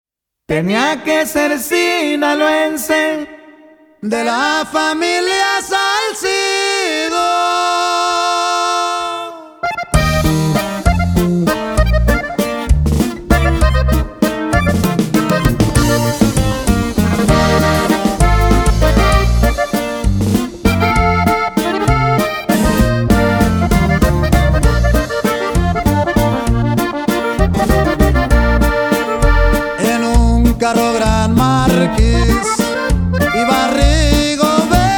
Música Mexicana, Latin